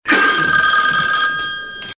• OLD TELEPHONE RING EFFECT.mp3
Old Rotary telephone ringing in my grandmother's kitchen.
old_telephone_ring_effect_6mq.wav